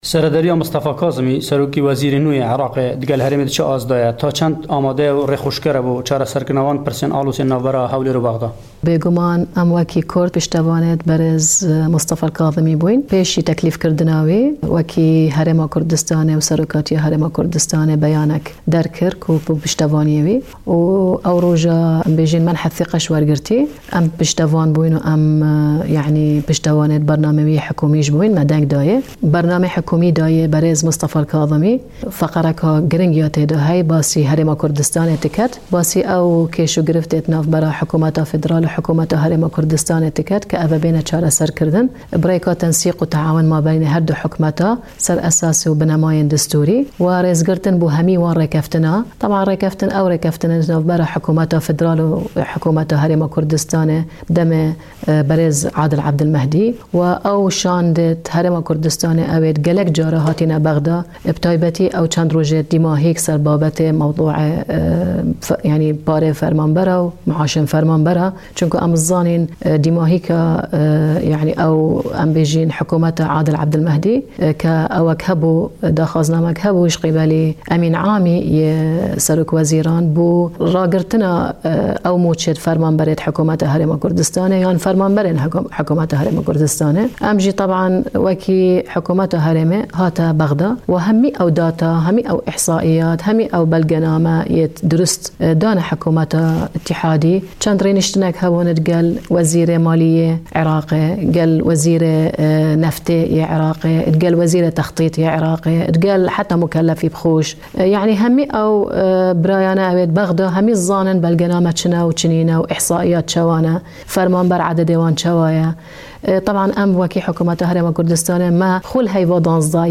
دکتۆر ڤیان سەبری سەرۆکی فڕاکسیۆنی پارتی دیموکراتی کوردستان لە پەرلەمانی عێراق لە وتووێژێکدا لەگەڵ دەنگی ئەمەریکا دەڵێت "پێویستە حکومەتی ناوەندی شایستەی حکومەتی هەرێمی کوردستان بدات بە فەرمانبەر نەک هەر ئەو فەرمانبەرانەن کە ژمارەکەیان وا حسابکراوە کە شەش سەدو هەشتاو شەش هەزارو بیست و یەک فەرمانبەرن چونکە پێشمەرگە و خێزانی شەهید و زیندانیانی سیاسیش هەن ئەمانە هەمووی حساب نەکراون."